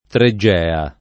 treggea [ tre JJ$ a ]